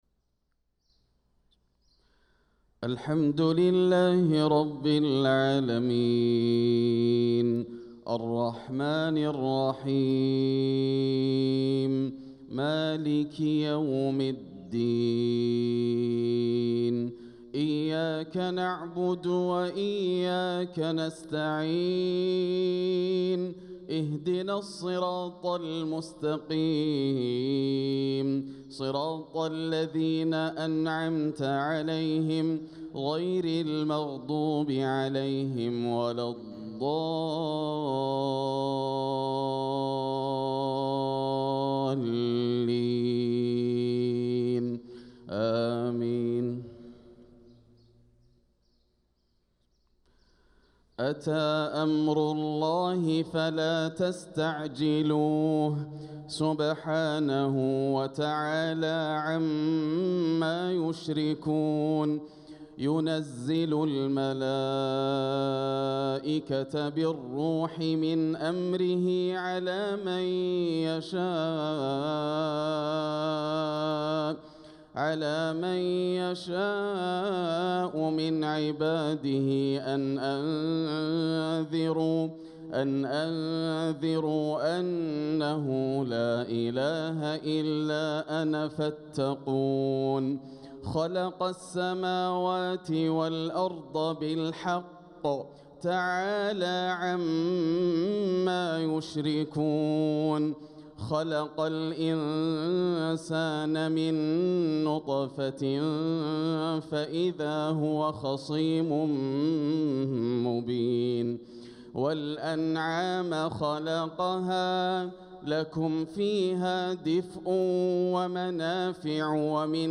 صلاة الفجر للقارئ ياسر الدوسري 20 صفر 1446 هـ
تِلَاوَات الْحَرَمَيْن .